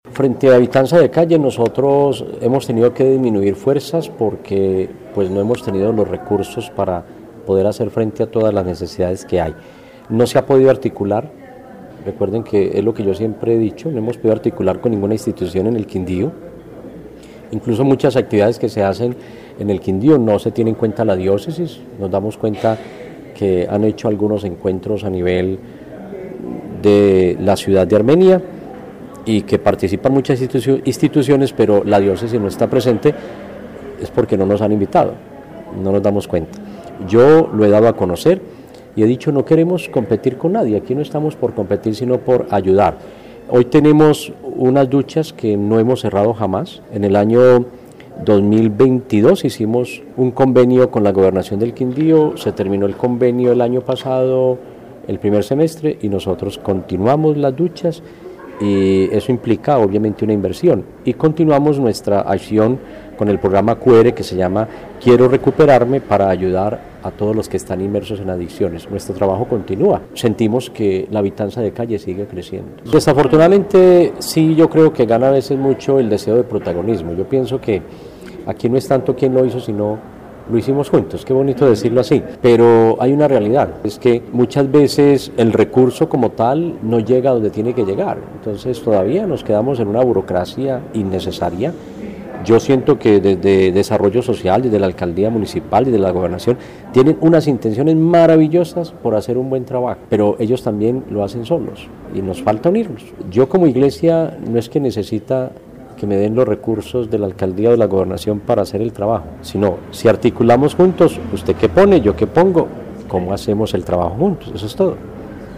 Obispo de la Diócesis de Armenia